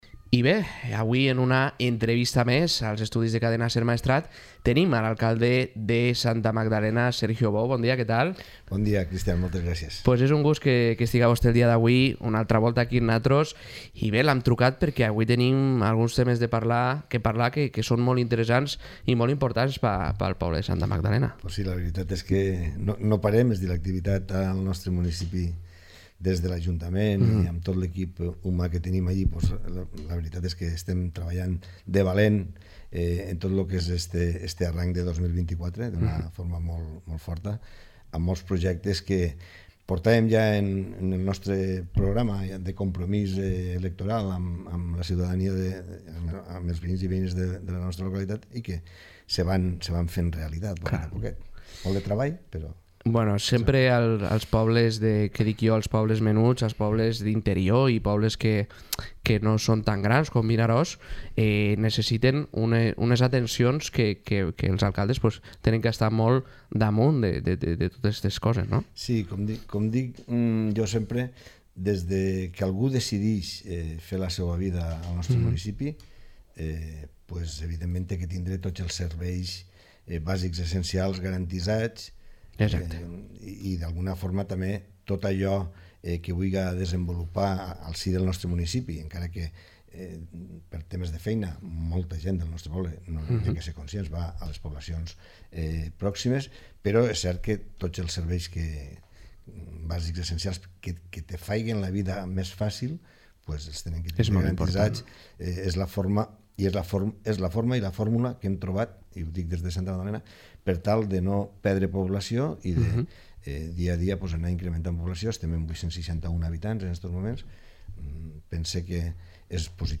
Podcast | Entrevista a Sergio Bou alcalde de Santa Magdalena